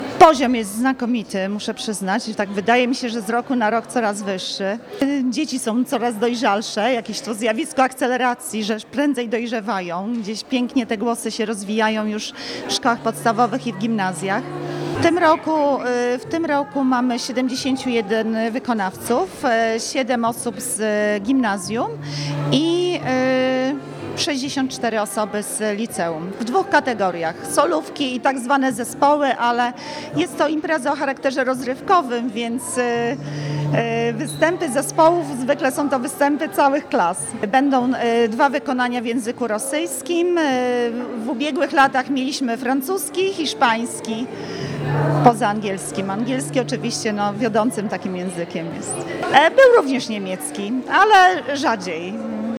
W placówce już po raz 13. odbył się Festiwal Piosenki Obcojęzycznej „Singing Red Bricks”.